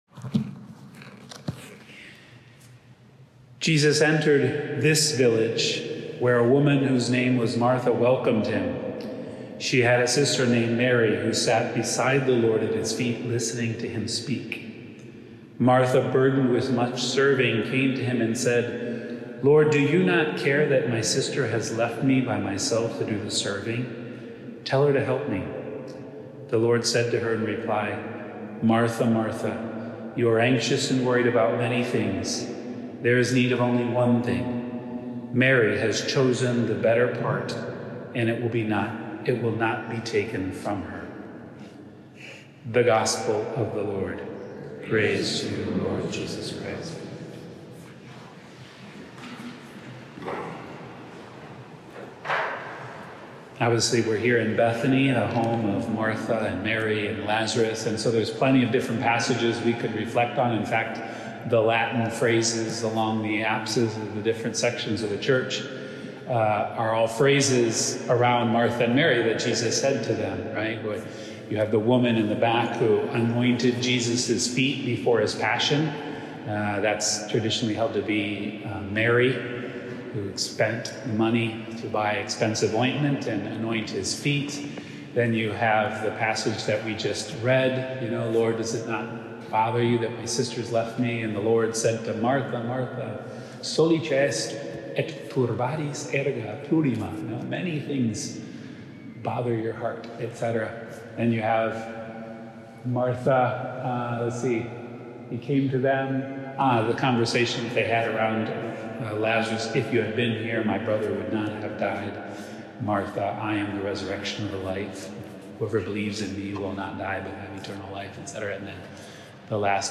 Day 6: Bethany From April 20-27